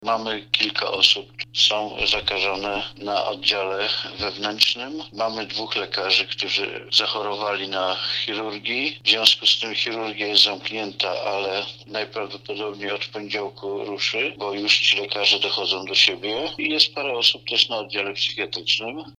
Mówi starosta powiatu tarnobrzeskiego Jerzy Sudoł.